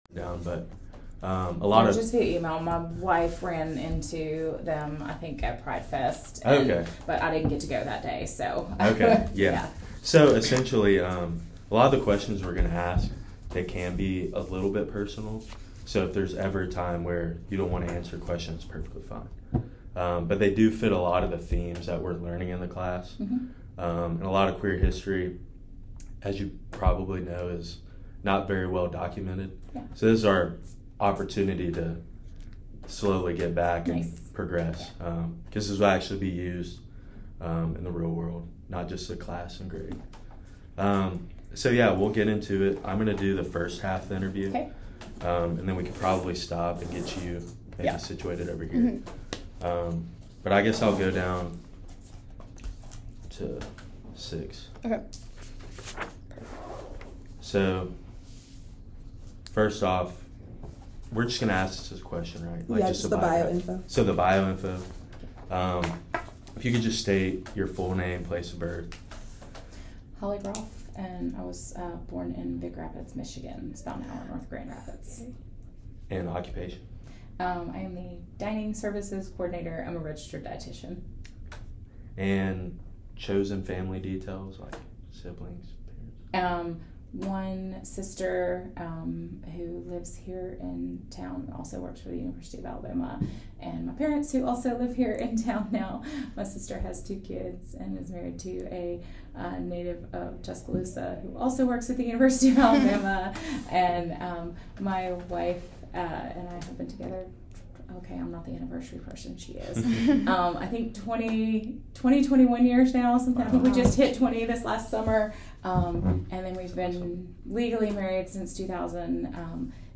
oral history interview
It is being conducted on November 9, 2023 in a conference room above Fresh Foods Dining Hall at the University of Alabama in Tuscaloosa, Alabama.